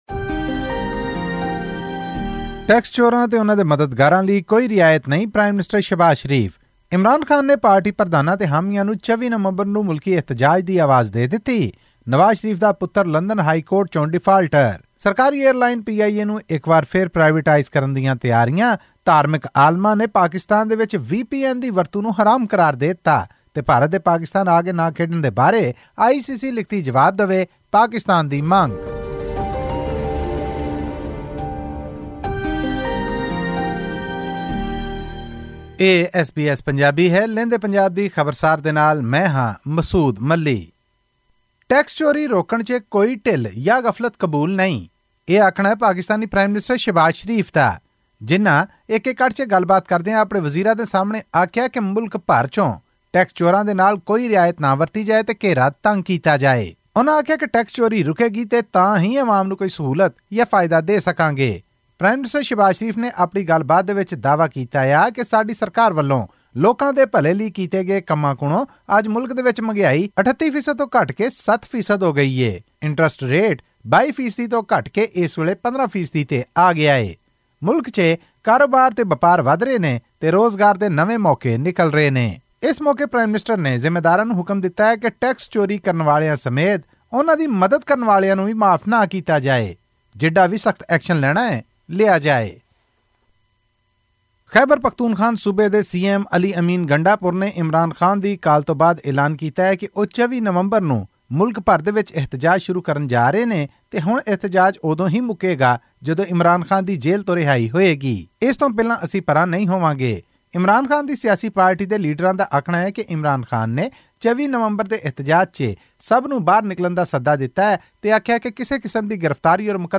ਇਸ ਭਰੋਸੇ ਤੋਂ ਬਾਅਦ ਫੈਡਰਲ ਸਰਕਾਰ ਨੇ ਪਾਕਿਸਤਾਨ ਇੰਟਰਨੈਸ਼ਨਲ ਏਅਰਲਾਈਨ ਨੂੰ ਨਿੱਜੀ ਹੱਥਾਂ ਵਿੱਚ ਦੇਣ ਵੱਲ ਤਵੱਜੋਂ ਦੇਣੀ ਸ਼ੁਰੂ ਕਰ ਦਿੱਤੀ ਹੈ। ਇਹ ਅਤੇ ਹੋਰ ਖ਼ਬਰਾਂ ਲਈ ਸੁਣੋ ਇਹ ਰਿਪੋਰਟ…